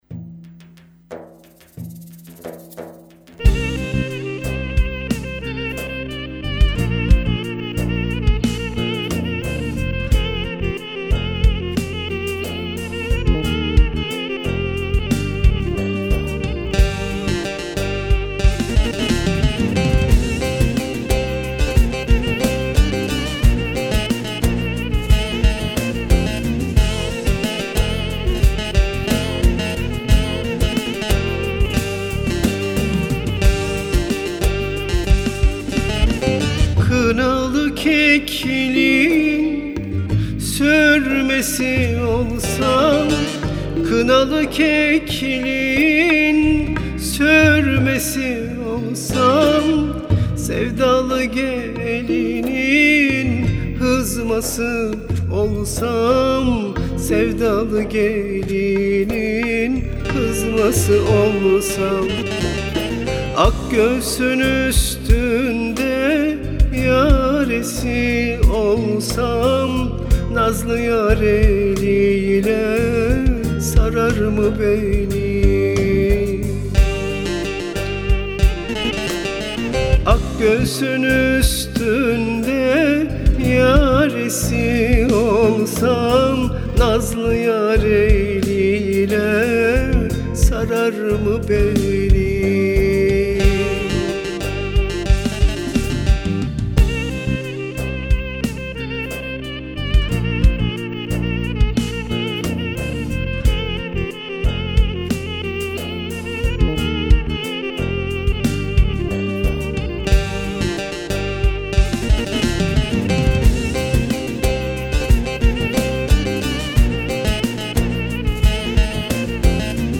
Eser Şekli : Halk Müziği
duygusal bir parça